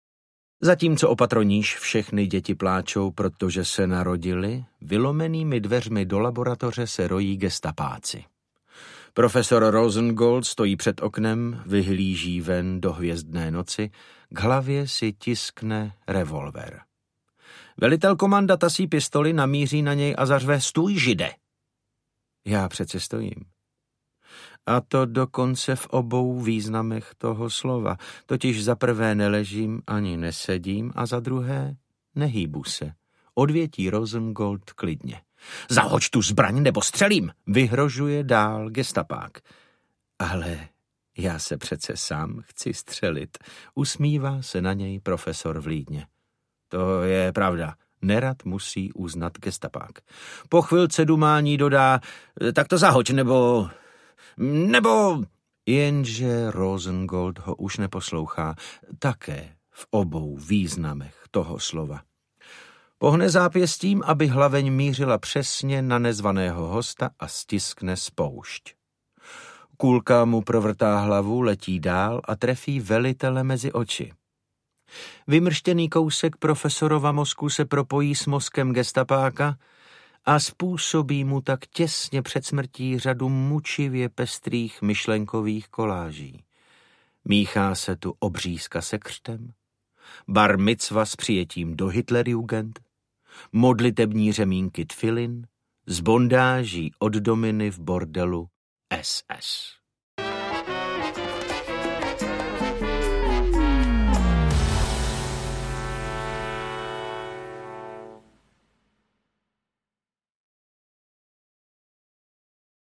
Pérák audiokniha
Ukázka z knihy
• InterpretDavid Novotný